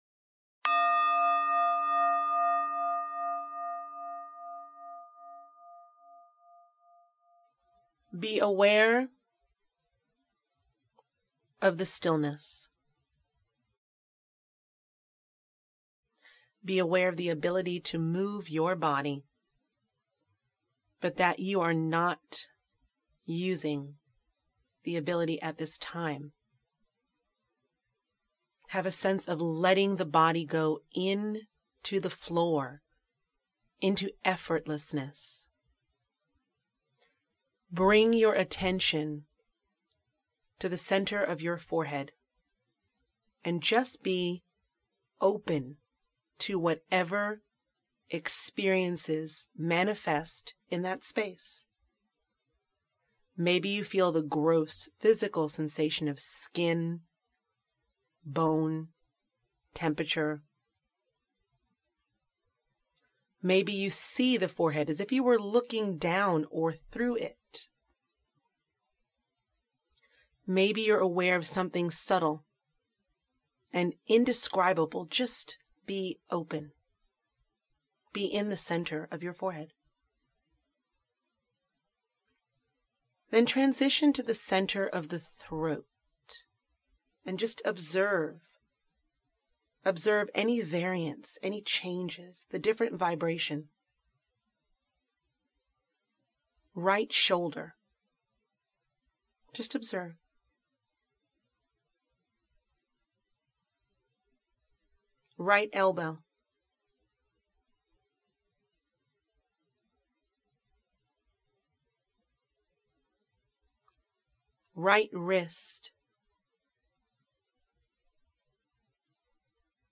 This is a deep relaxation practice. It can be used as a preparation for meditation in conjunction with savasana.